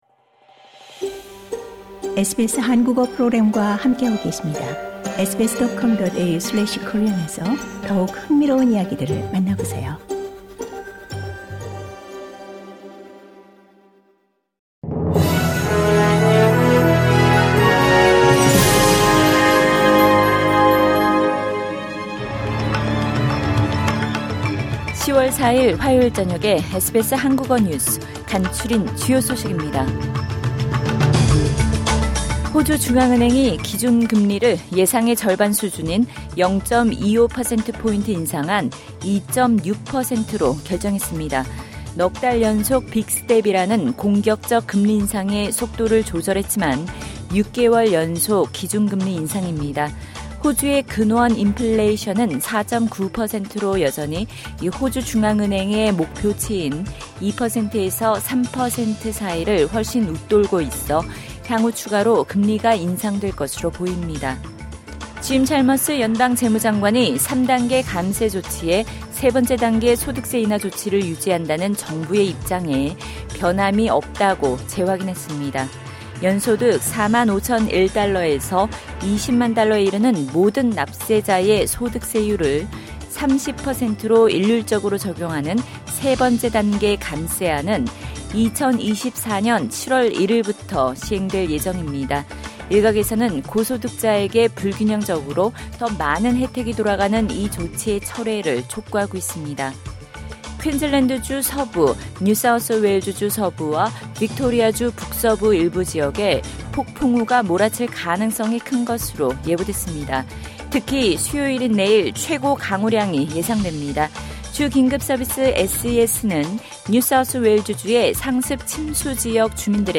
SBS 한국어 저녁 뉴스: 2022년 10월 4일 화요일
2022년 10월 4일 화요일 저녁 SBS 한국어 간추린 주요 뉴스입니다.